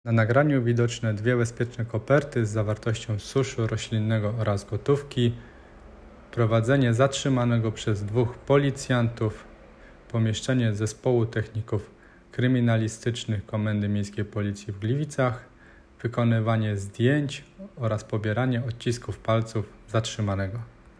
Nagranie audio Audiodyskrypcja.m4a